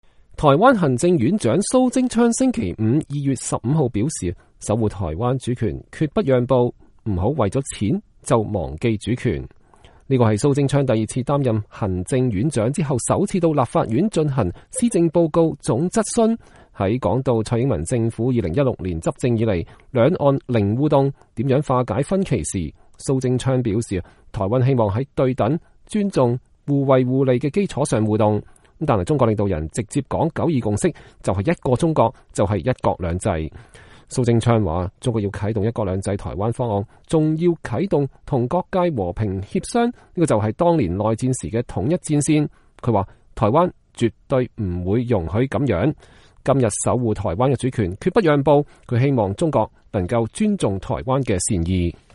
這是蘇貞昌第二次擔任行政院長後首次到立法院進行施政報告總質詢。